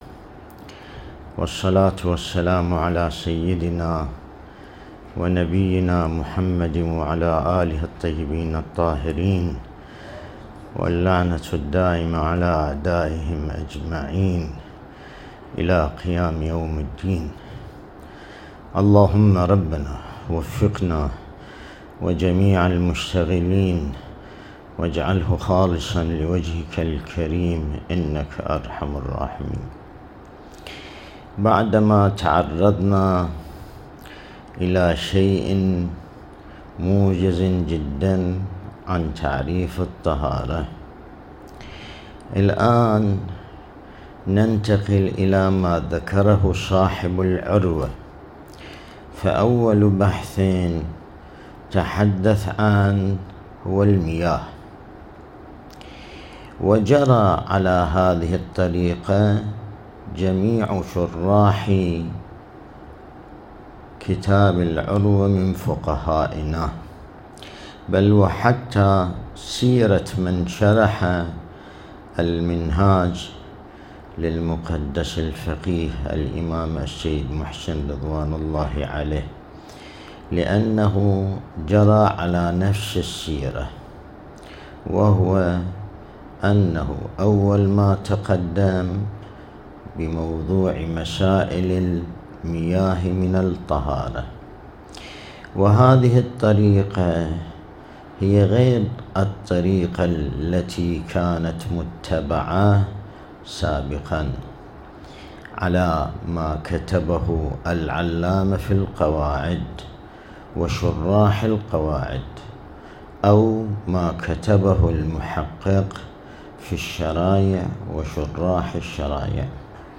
الدرس الاستدلالي شرح بحث الطهارة من كتاب العروة الوثقى